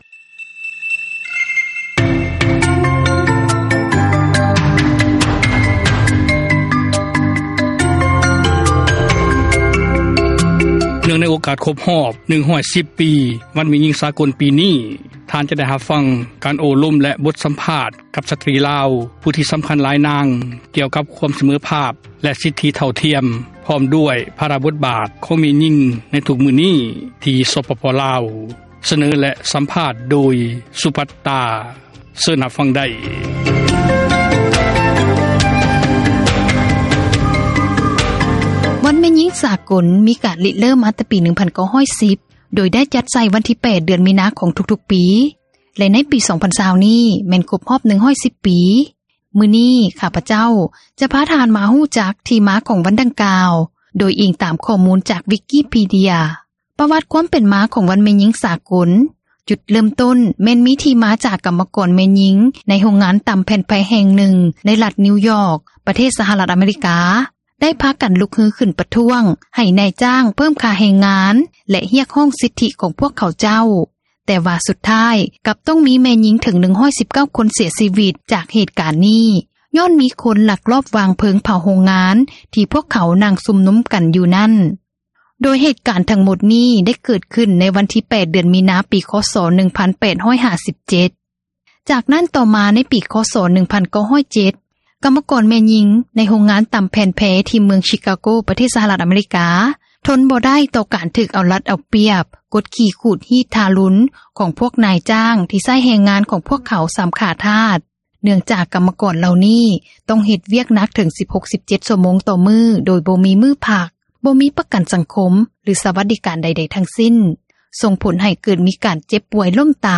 ເນື່ອງໃນໂອກາດ ຄົບຮອບ 110 ປີ ວັນແມ່ຍິງ ສາກົນ ປີນີ້ ທ່ານ ຈະໄດ້ຮັບຟັງ ບົດສຳພາດ ສະຕຣີລາວ ຜູ້ທີ່ສຳຄັນ ຫຼາຍທ່ານ ກ່ຽວກັບ ຄວາມສເມີພາບ ແລະ ສິດທິຍິງຊາຍ ເທົ່າທຽມກັນ.